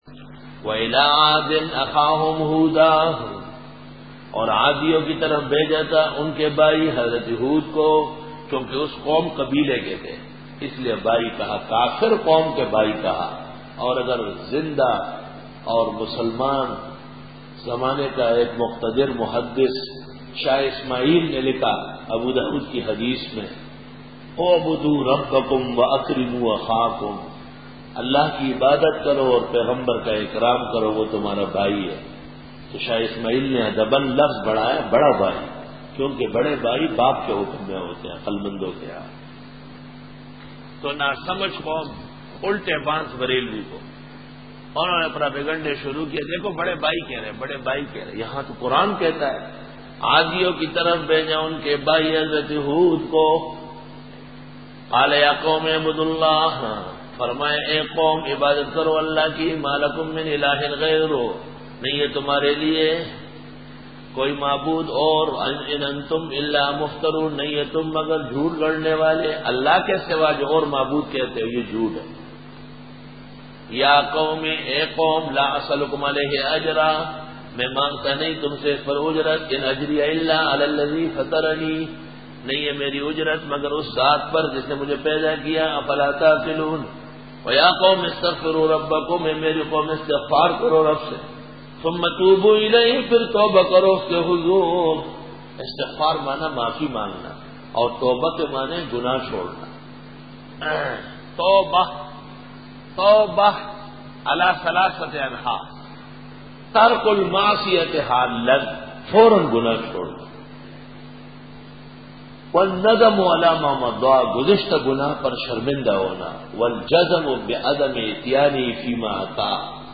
Bayan
Dora-e-Tafseer